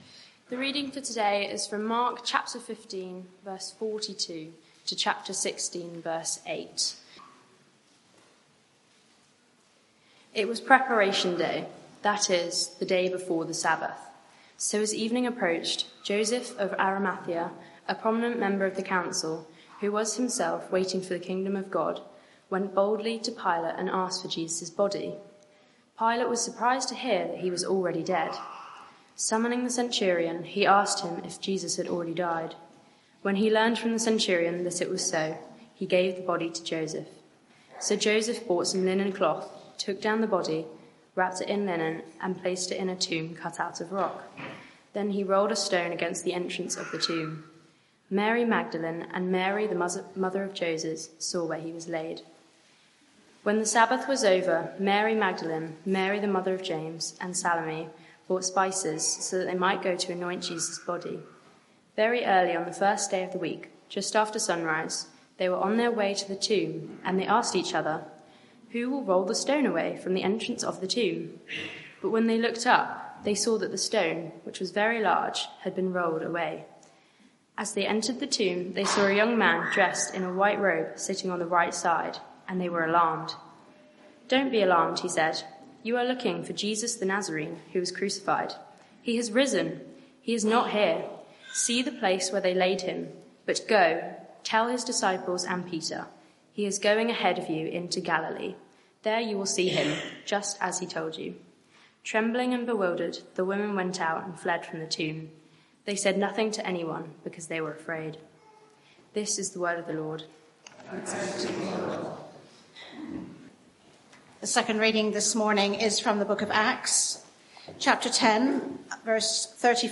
Media for 9:15am Service on Sun 31st Mar 2024 09:15 Speaker
Passage: Mark 15:42-16:8 Series: The Road to Glory Theme: Sermon (audio)